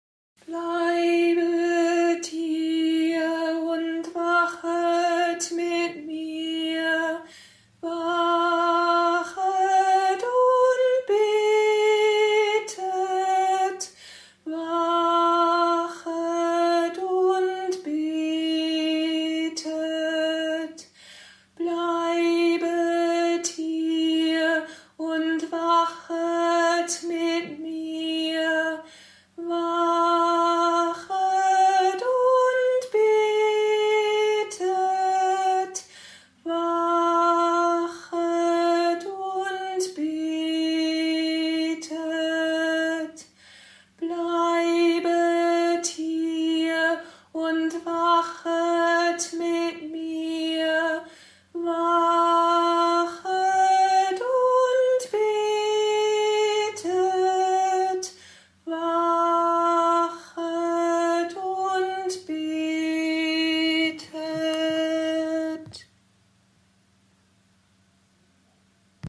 Das Taizé-Lied „Bleibet hier und wachet mit mir“ für die Andachten am Gründonnerstag und am Karfreitag können Sie sich
Lieder und Gesänge
zum Anhören und Mitsingen